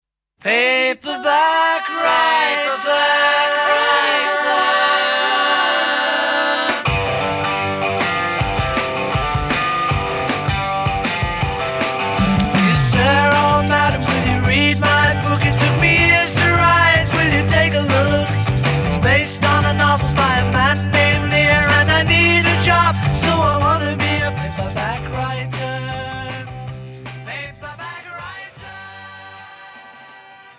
zpěv, basová kytara
zpěv, kytara
zpěv, sólová kytara
bicí, tamburína